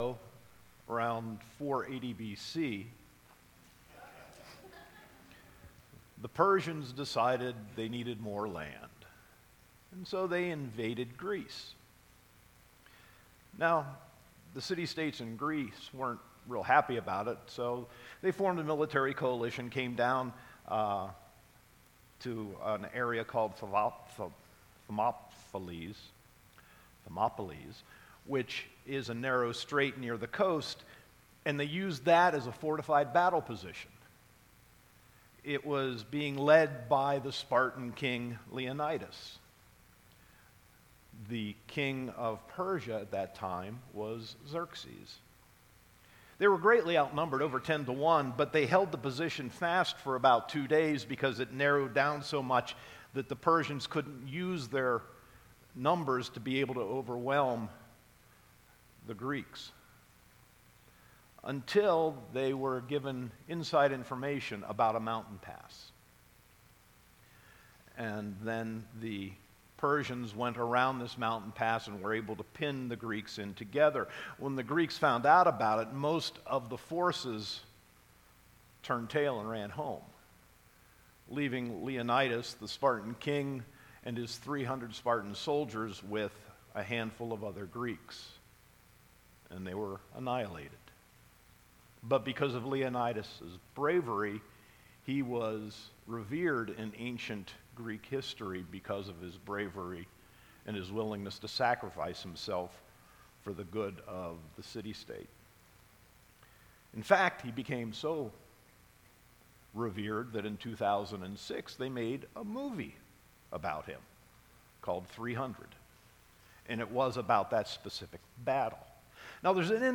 Sermon 9.16.2018